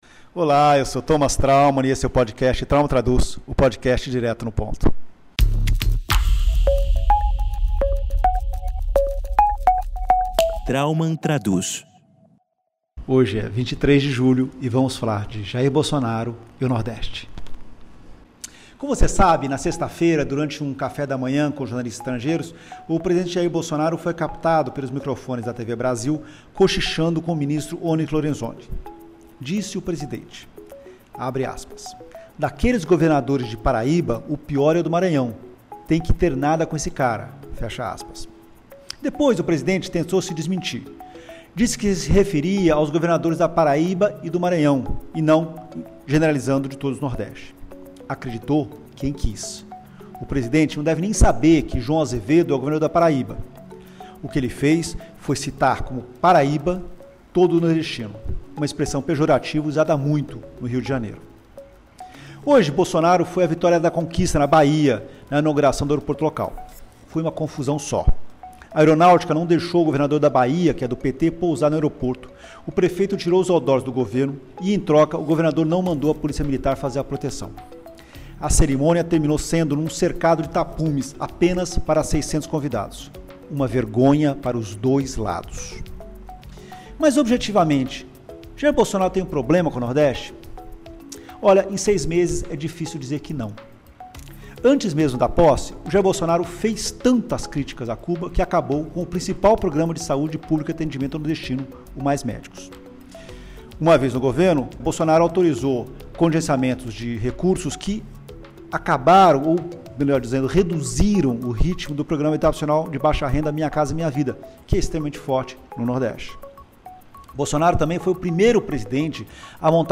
jornalista Thomas Traumann analisa a situação neste episódio do podcast Traumann Traduz: